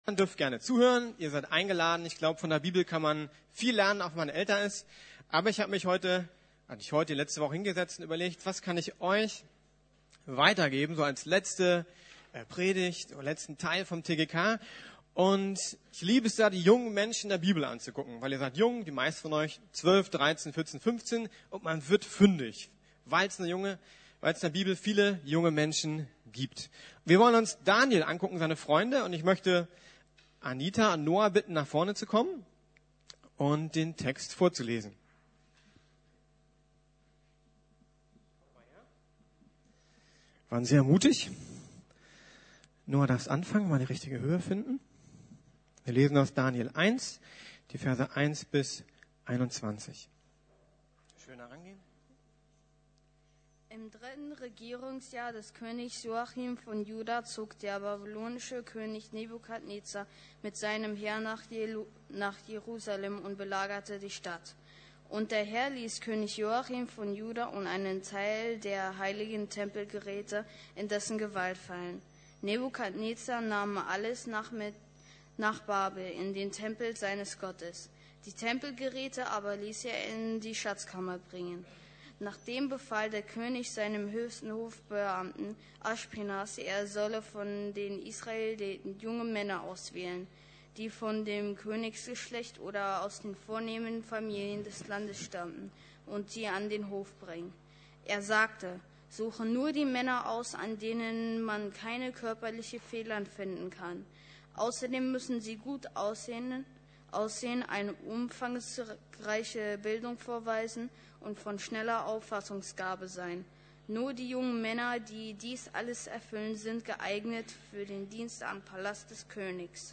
Eine Entscheidung für´s Leben ~ Predigten der LUKAS GEMEINDE Podcast